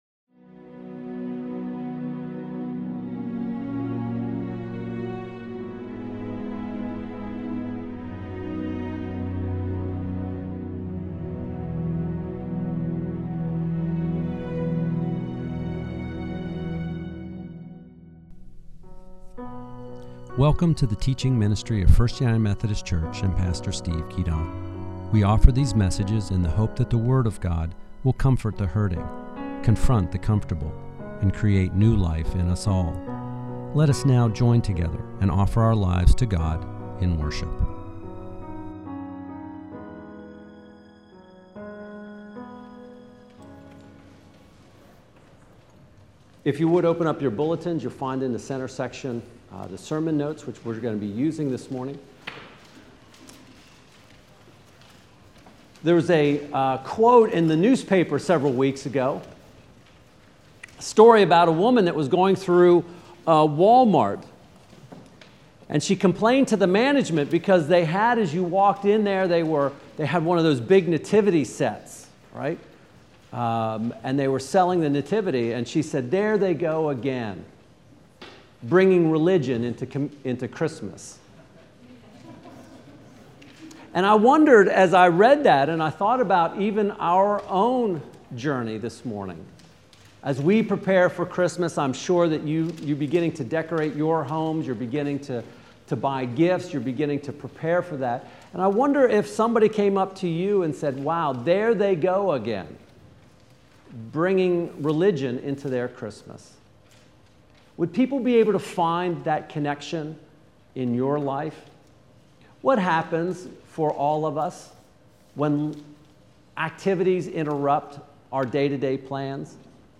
Second Advent Sermon Audio: Interrupted Life Part 2